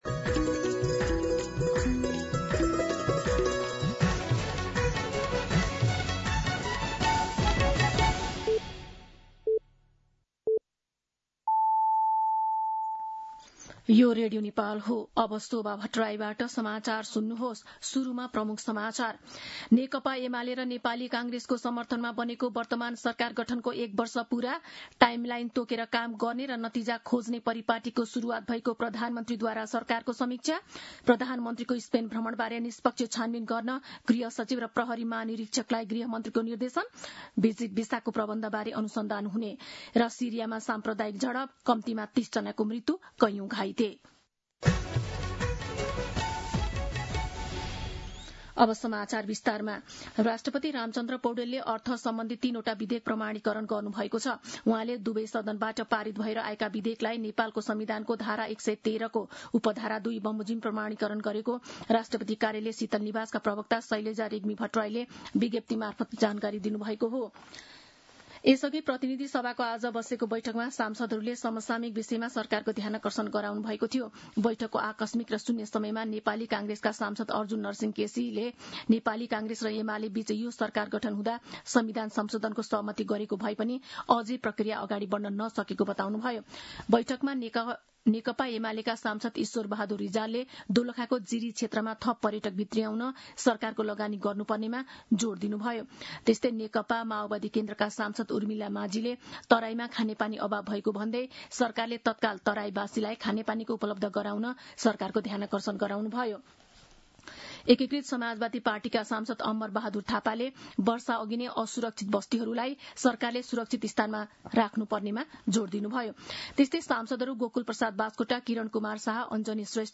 दिउँसो ३ बजेको नेपाली समाचार : ३० असार , २०८२